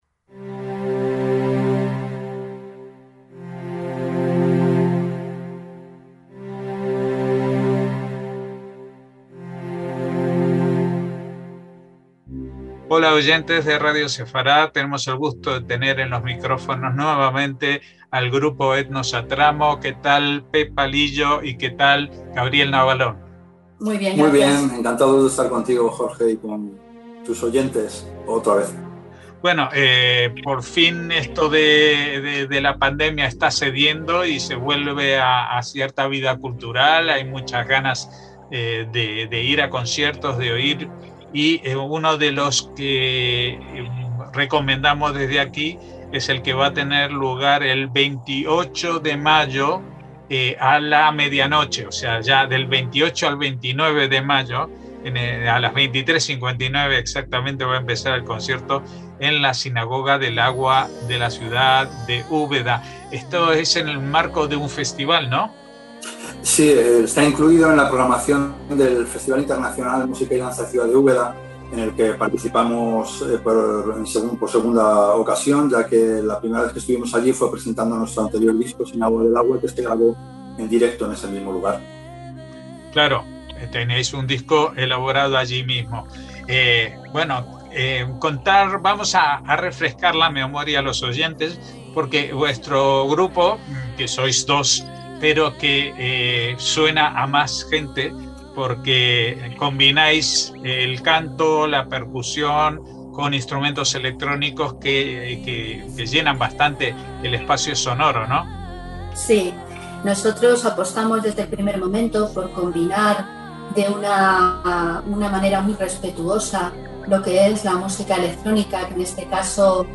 Hablamos con ellos de este concierto y de su concepto musical, que aborda no sólo el repertorio sefardí, sino también el ashkenazí y aún el judeo-yemenita.